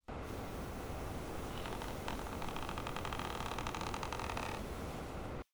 房间内部场景2.wav